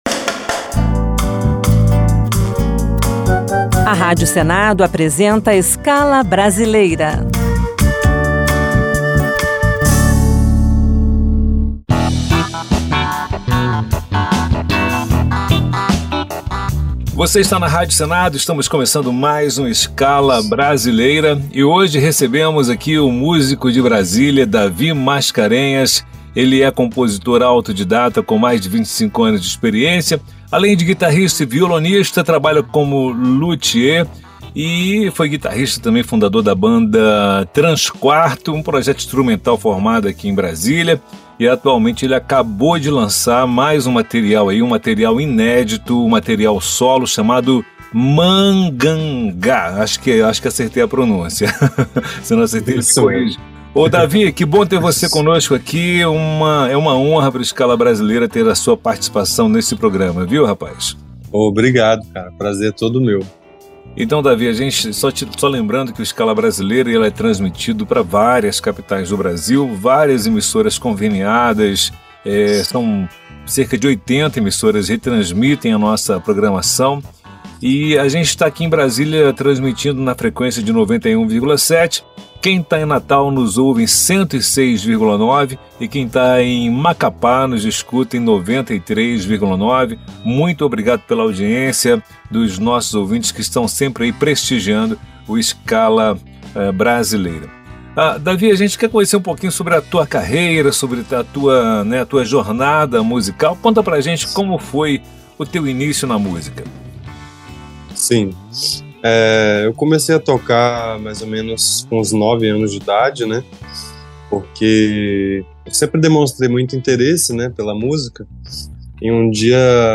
Nesta entrevista